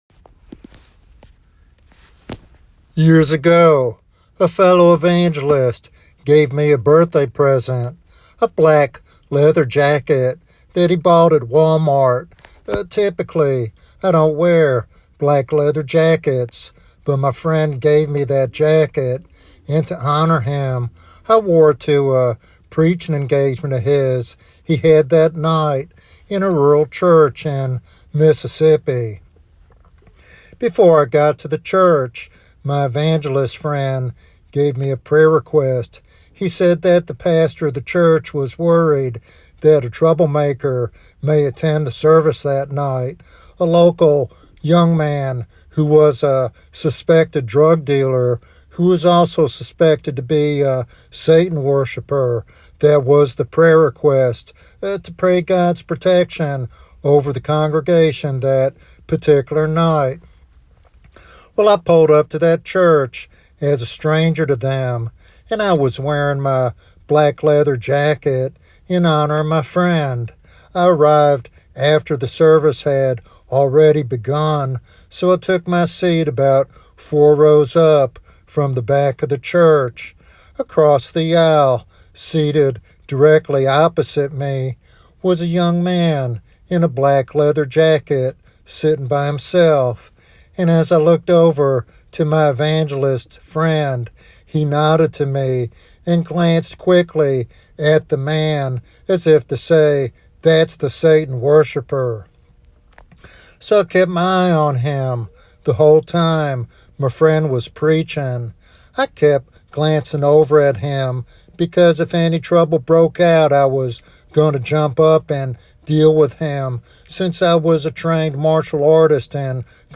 In this devotional sermon
Sermon Outline